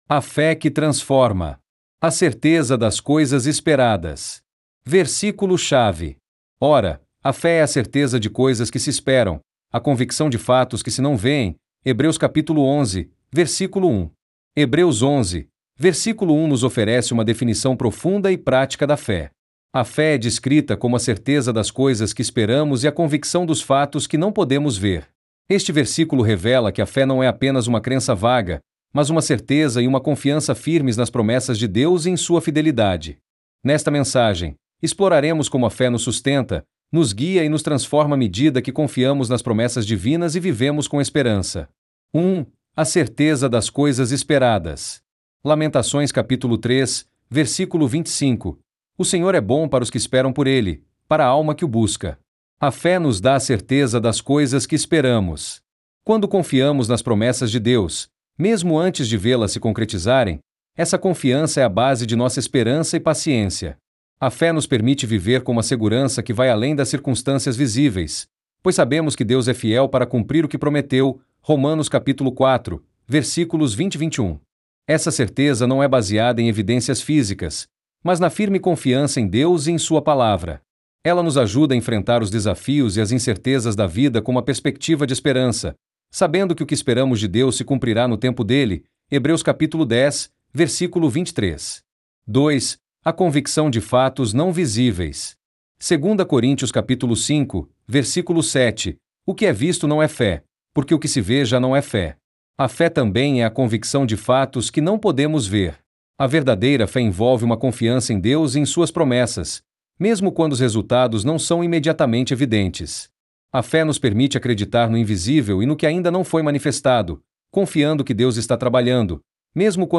DEVOCIONAL